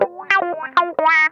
ITCH LICK 3.wav